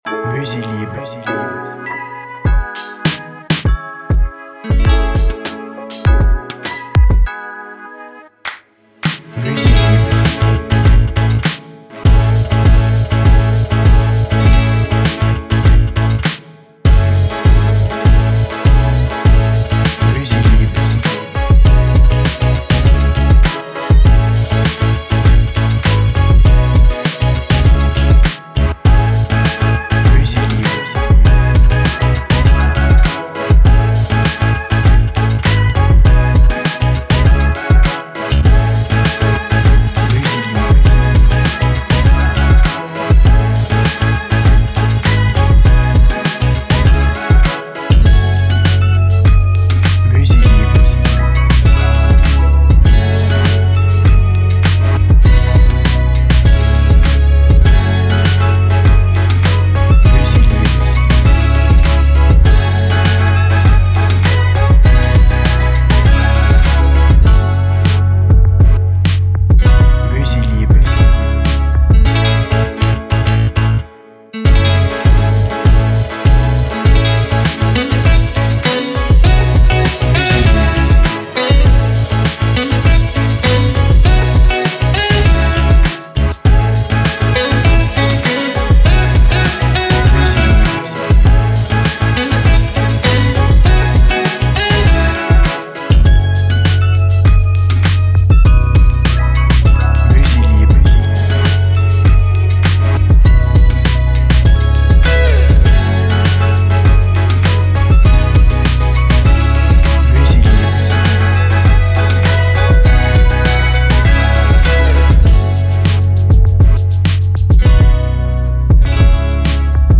BPM Rapide